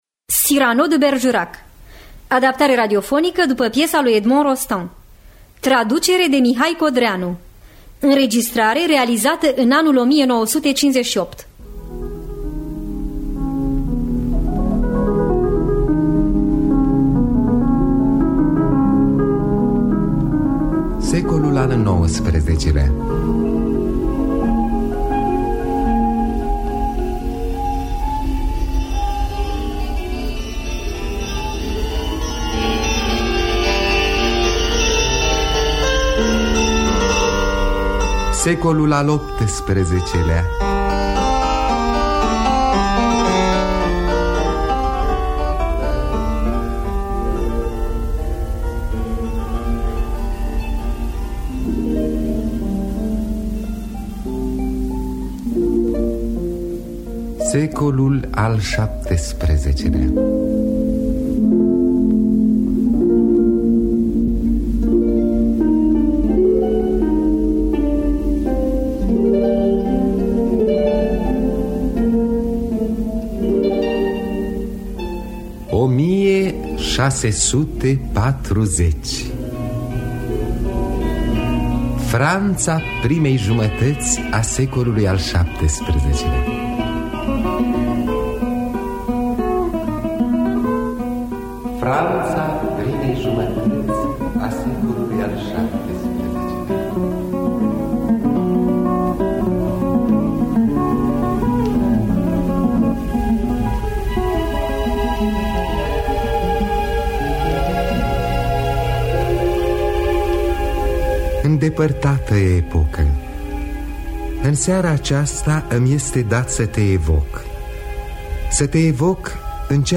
Cyrano de Bergerac de Edmond Rostand – Teatru Radiofonic Online